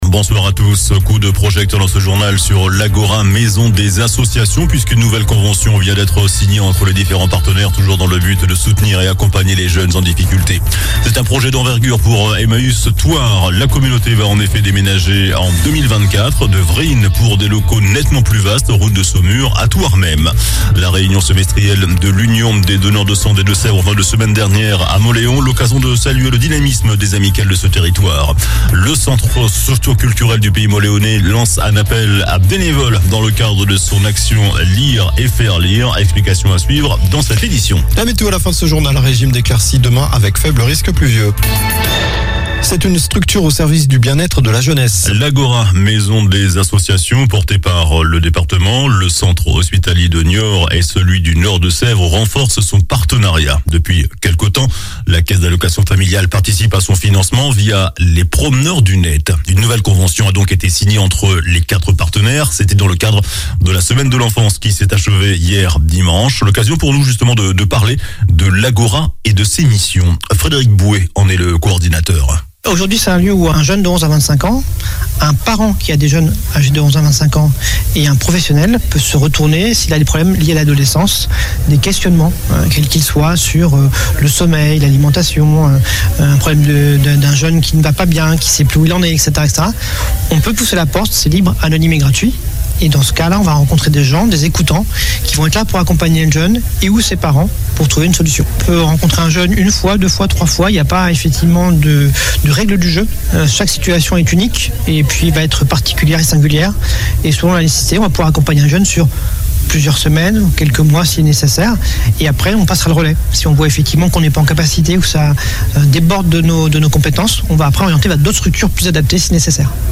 JOURNAL DU LUNDI 21 NOVEMBRE ( SOIR )